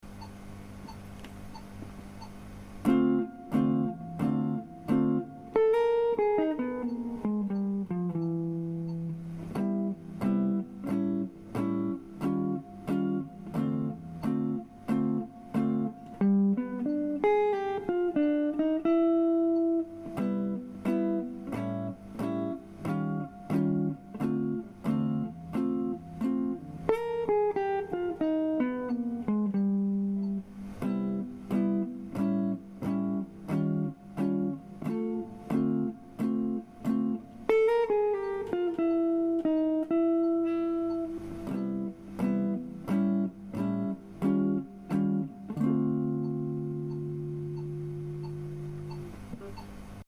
【オルタードスケール】
テンションコードがジャズっぽい美しい響きをかもし出すように、オルタードスケールもジャズには欠かせないスケールです。